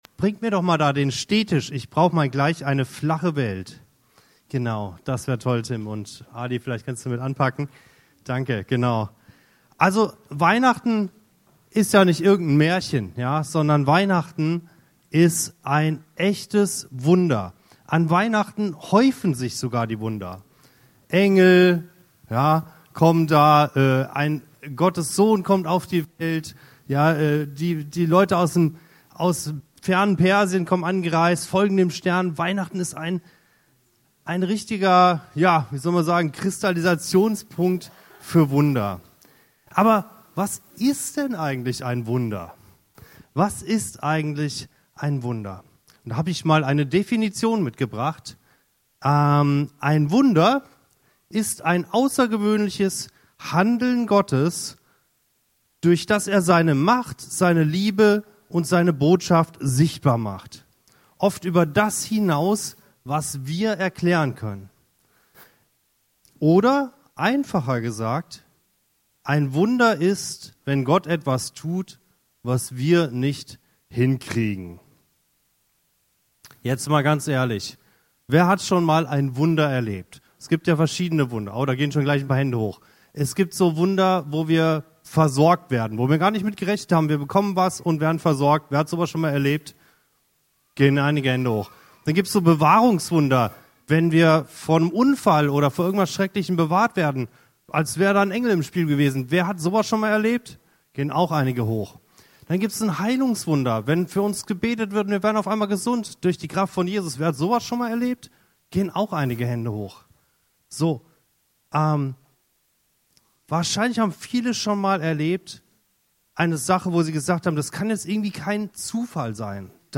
Diese Predigt zum Heiligabend knüpft an das Musical „Zeit für Wunder“. Ausgehend von Psalm 77,15 geht es darum, die Wunder Gottes im Alltag wieder wahrzunehmen, neu zu staunen und dem Weihnachtsgeschehen mit einem offenen Herzen zu begegnen.